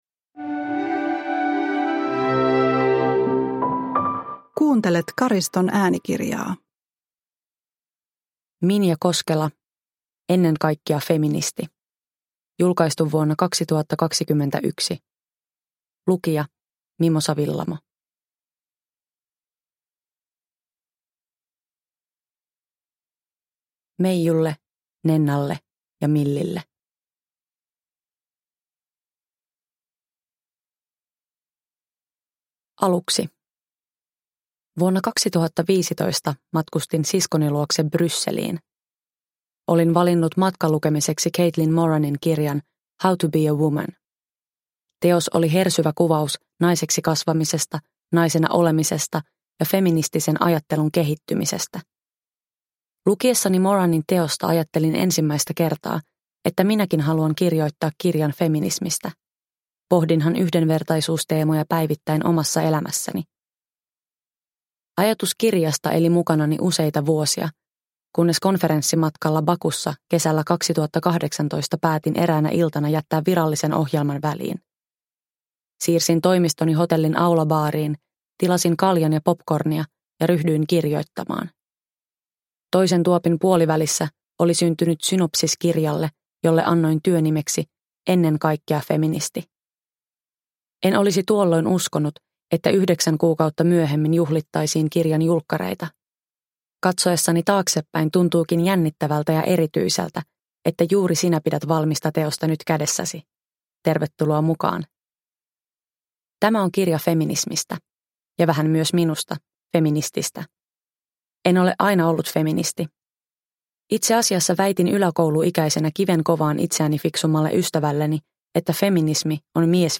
Ennen kaikkea feministi – Ljudbok – Laddas ner